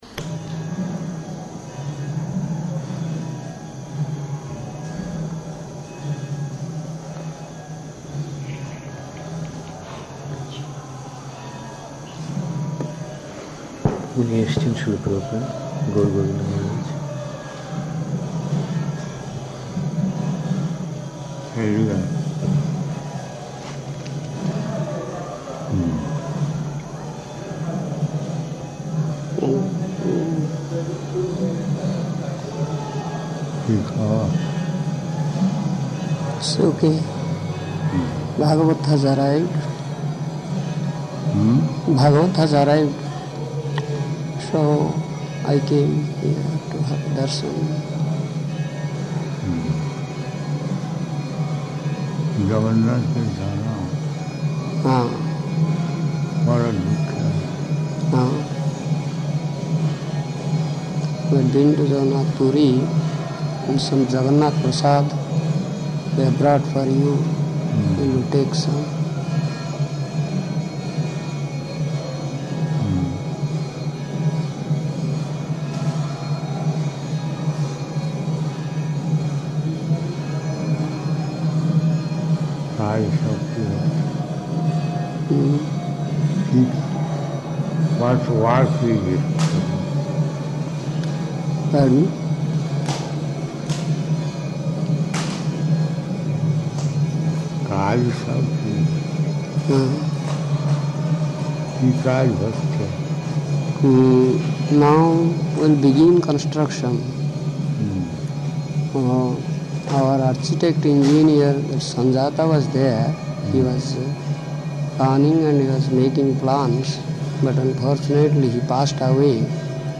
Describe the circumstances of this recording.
-- Type: Conversation Dated: November 3rd 1977 Location: Vṛndāvana Audio file